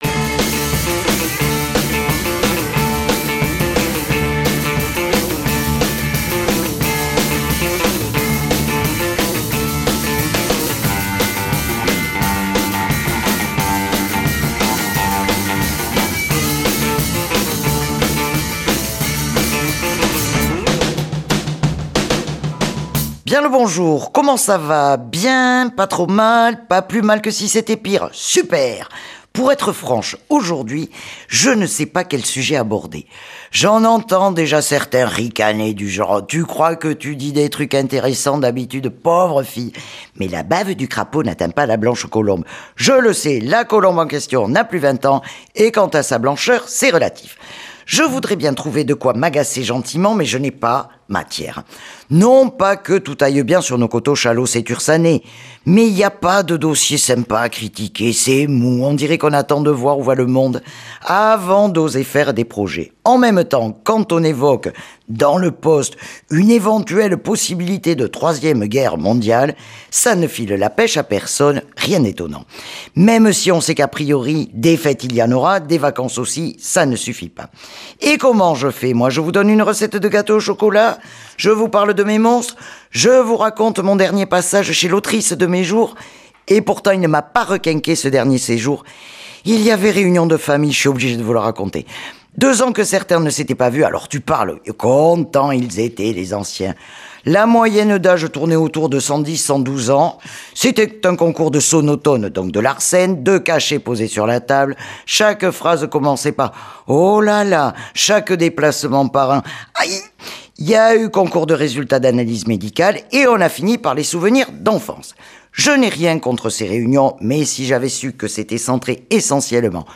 Un programme musical axé principalement sur les morceaux Soul et Rythm and Blues de la fin du XXème siècle.